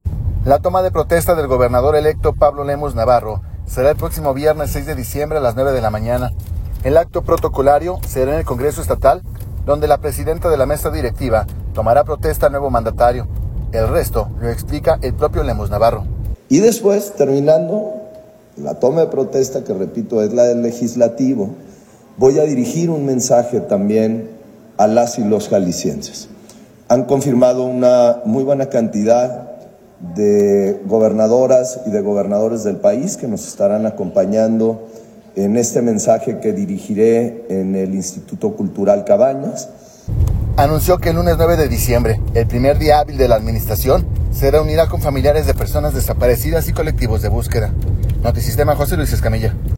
El resto lo explica el propio Lemus Navarro.